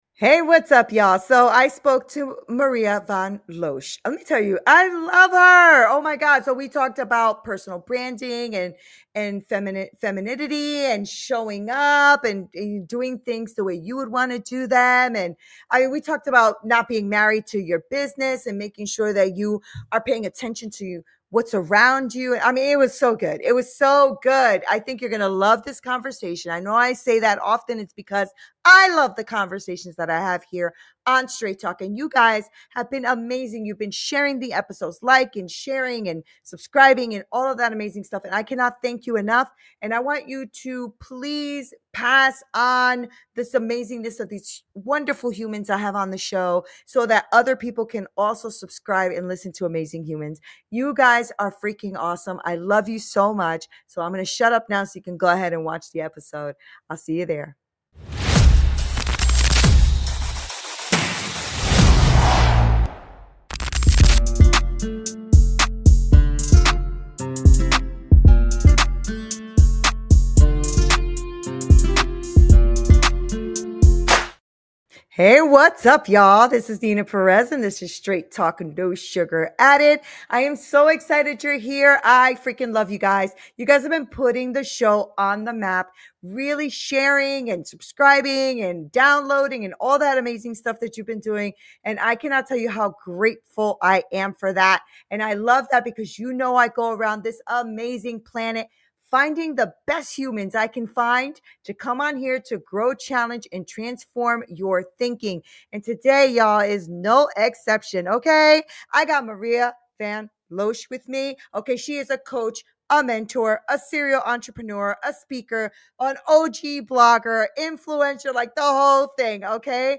Dive into this transformative conversation and ignite your brand's potential today!